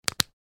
Flashlight Off.ogg